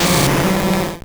Cri de Volcaropod dans Pokémon Or et Argent.